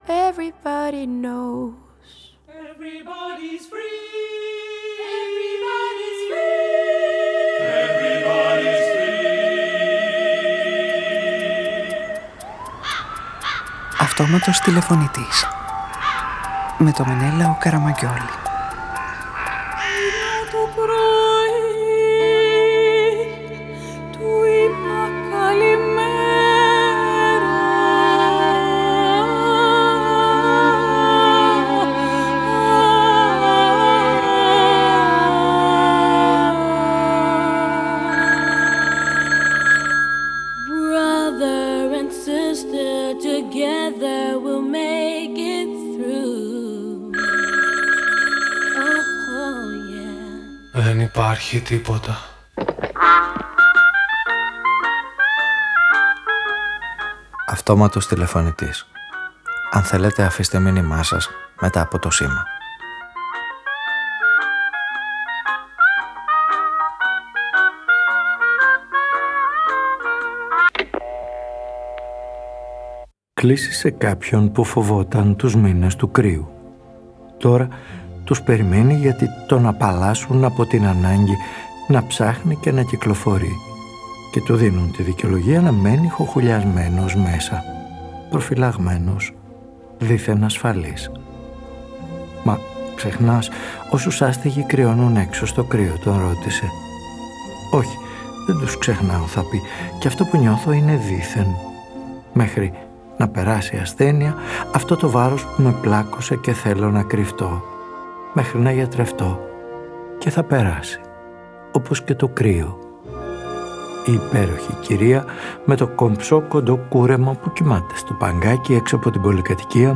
Μια κομψή κυρία που κοιμάται στο παγκάκι δίπλα στο συντριβάνι μαζί με μια ασπρόμαυρη γάτα, ο αυτοκράτορας Οκτάβιος κι ένας καταθλιπτικός εκφωνητής γίνονται οι ήρωες μιας ραδιοταινίας που φιλοδοξεί να γίνει ένα εμψυχωτικό μήνυμα για όσους φοβούνται τους μήνες του κρύου.
Ραδιοφωνικη Ταινια